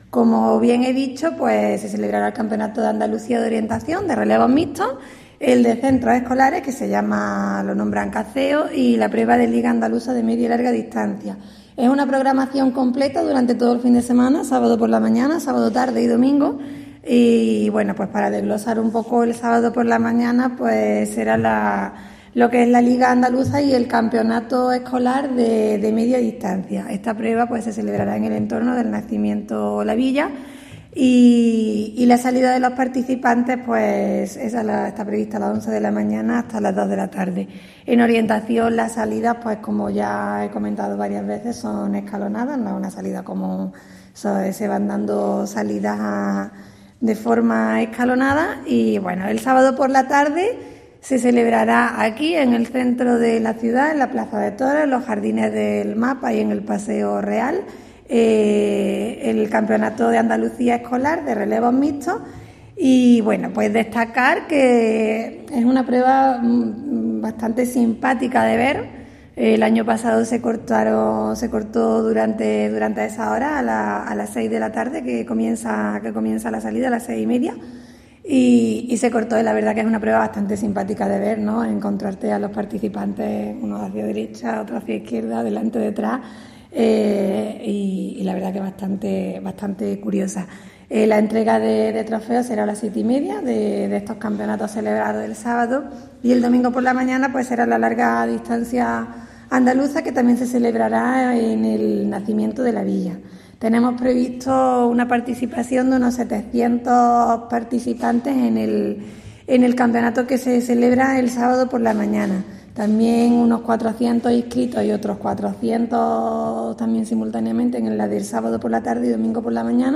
Cortes de voz E. Galán 887.86 kb Formato: mp3